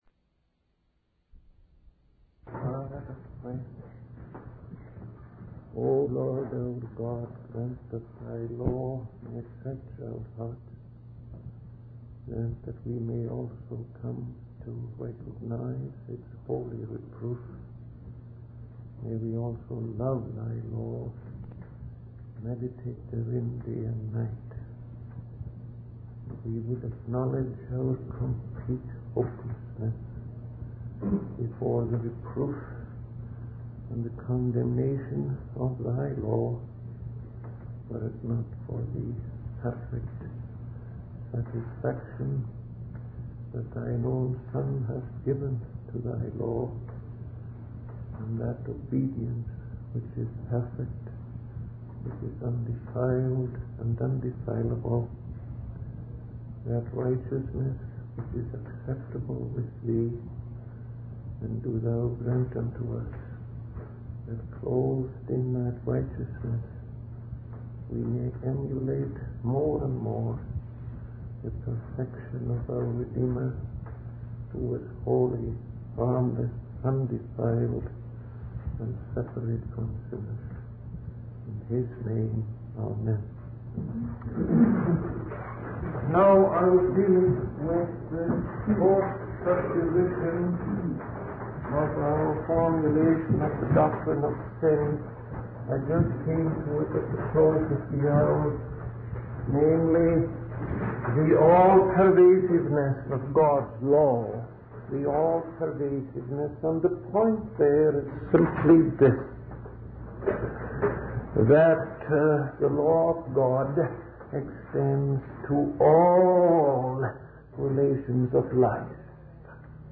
In this sermon, the preacher discusses the fourth position of the doctrine of sin. He emphasizes the all-pervadedness of God's law, stating that it extends to all aspects of life and we are always obligated to love and serve God. The preacher mentions three Latin terms - pollution, blame, and liability - which are related to sin.